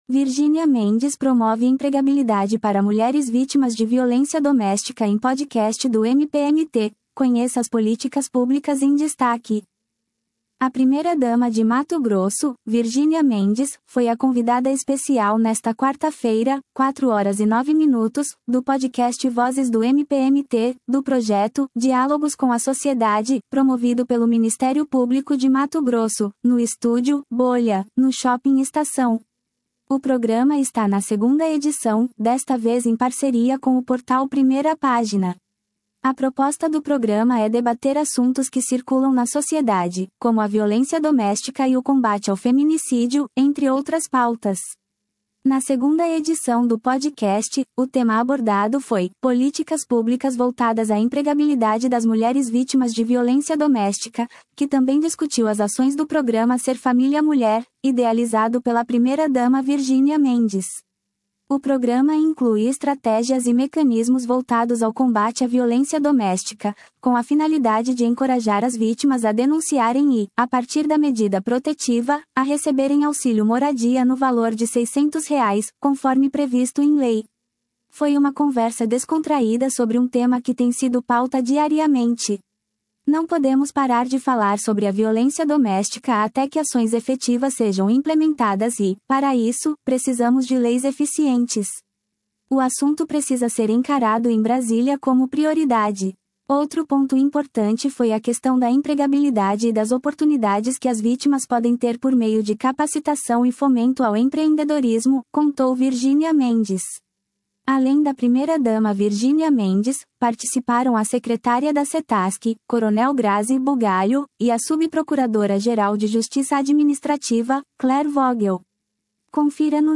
Além da primeira-dama Virginia Mendes, participaram a secretária da Setasc, Cel Grasi Bugalho, e a Subprocuradora-Geral de Justiça Administrativa, Claire Vogel.